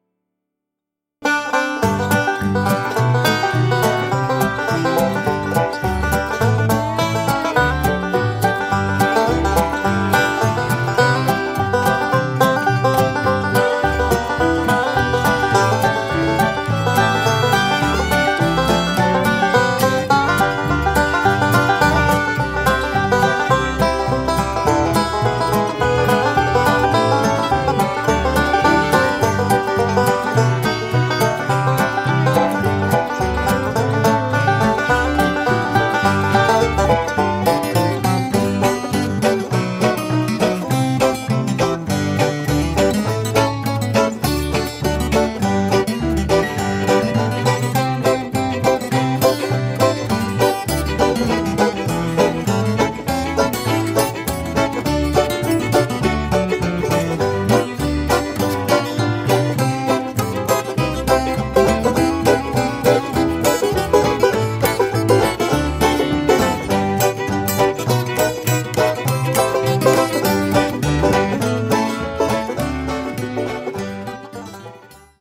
5-string banjo